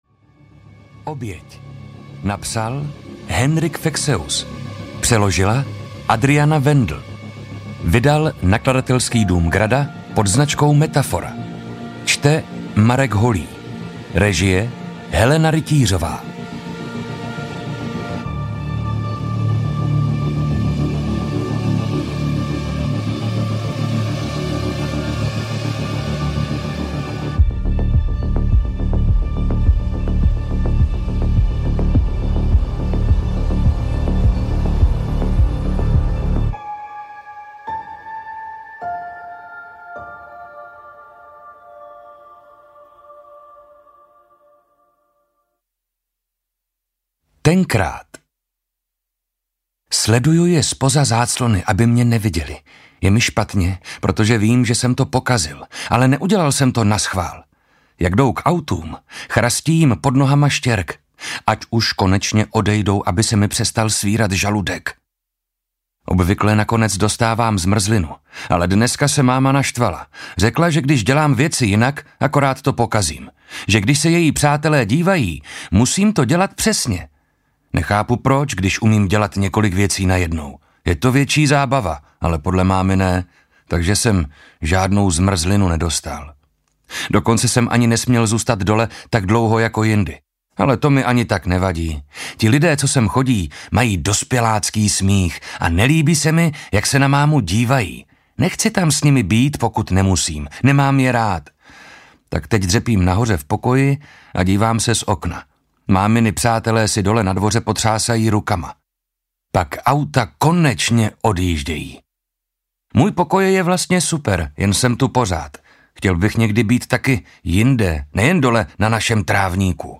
Oběť audiokniha
Ukázka z knihy
• InterpretMarek Holý